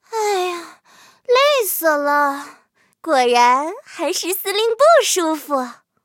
M10狼獾战斗返回语音.OGG